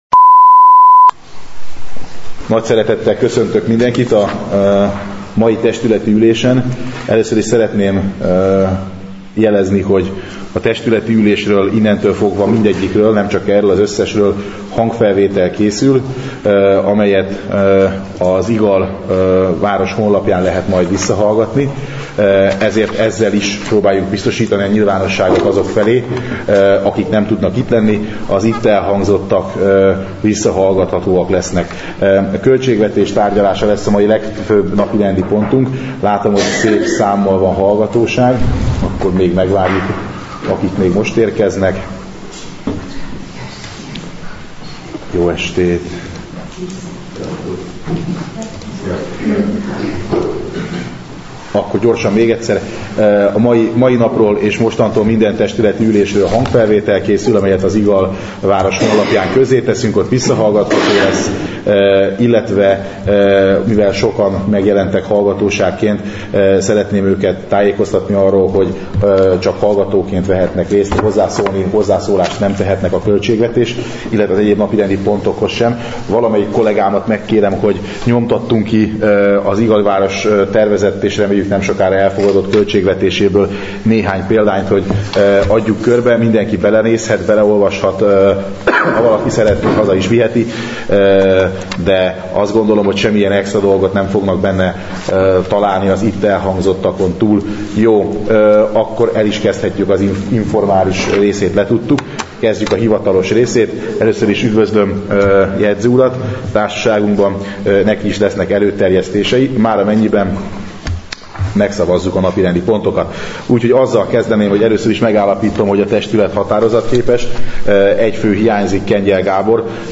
Képviselő-testületi ülések hanganyagai: 2025. március 12.